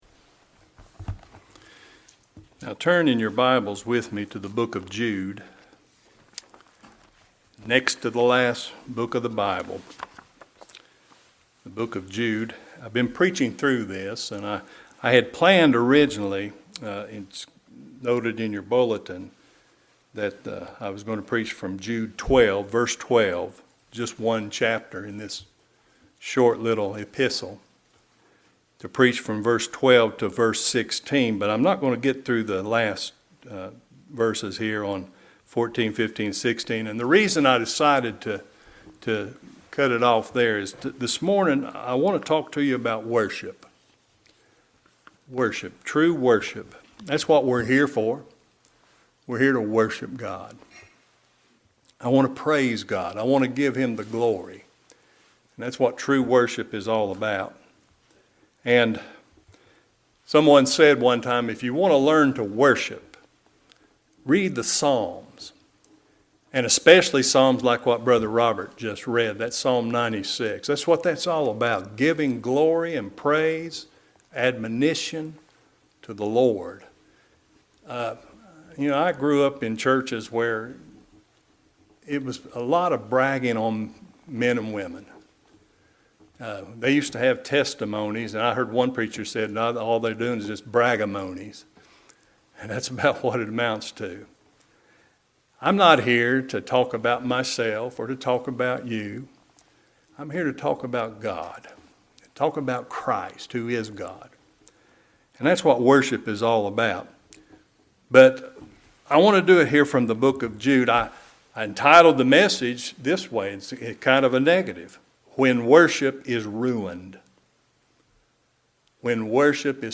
When Worship Is Ruined | SermonAudio Broadcaster is Live View the Live Stream Share this sermon Disabled by adblocker Copy URL Copied!